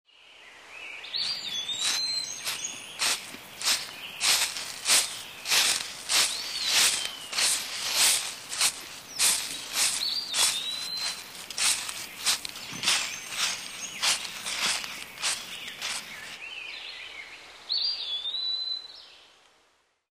Звуки шагов в лесу
• Качество: высокое
Скачивайте реалистичные записи шагов по мягкой траве, хрустящему осеннему ковру из листьев и утоптанным земляным тропам.